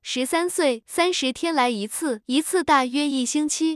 tts_result_15.wav